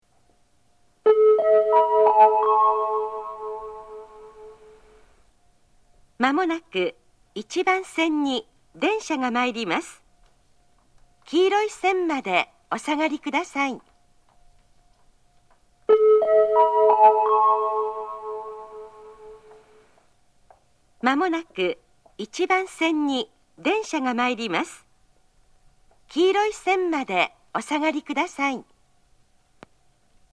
自動放送
仙石旧型（女性）
接近放送
仙石旧型女性の接近放送です。同じ内容を2度繰り返します。
鳴動は遅めです。